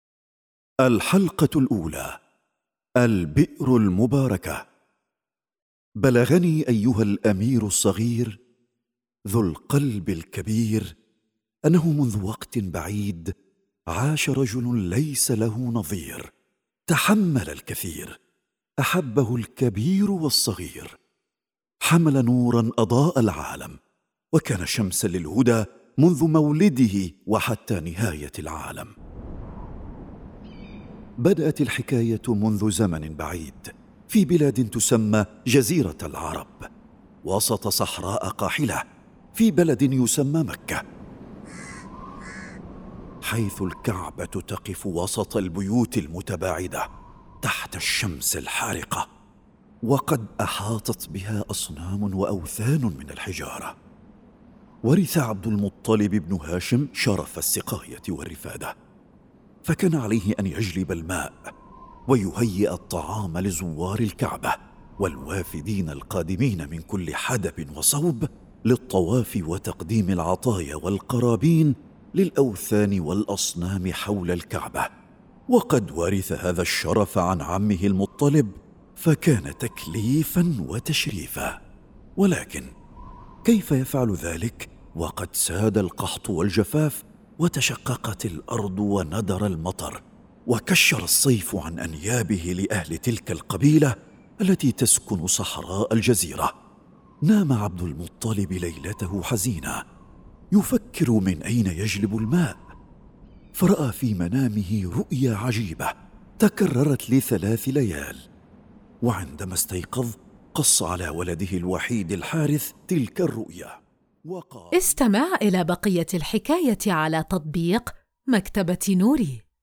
كل قصة يتم تقديمها بصوت جذاب جميل، مع مؤثرات لتحفيز الطفل على التفاعل والاندماج في عالم الحكايات.